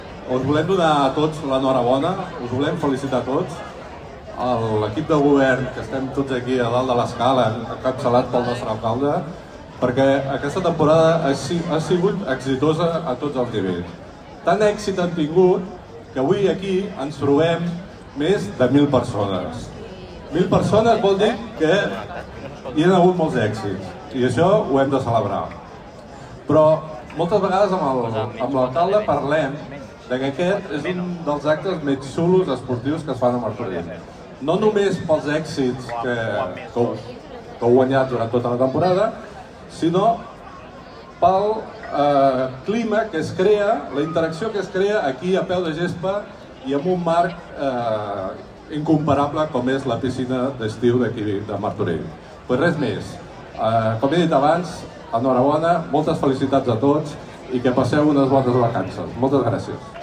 Aquest vespre s’ha celebrat a la Piscina Municipal d’Estiu del CIES la gala de reconeixement als èxits esportius que organitza l’Ajuntament de Martorell.
Gerard Mimó, regidor d'Esports de l'Ajuntament de Martorell